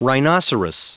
Prononciation du mot : rhinoceros